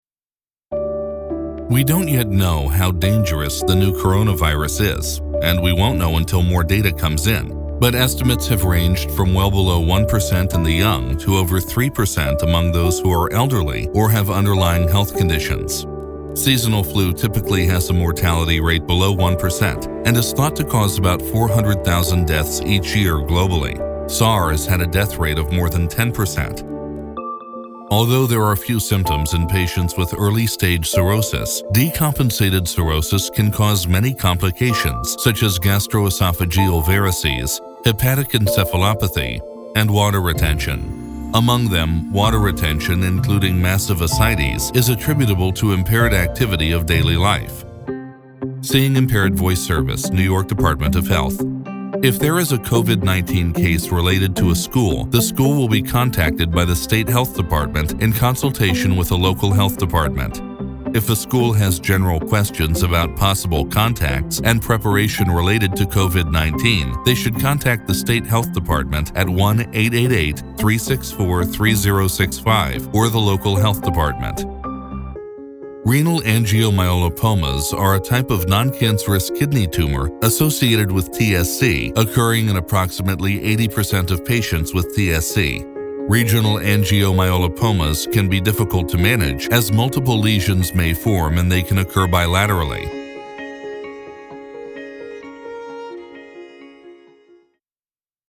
A dynamic voice with range from millennial to movie trailer
Medical Narration Including COVID-19
POWERFUL, VERSATILE VOICE-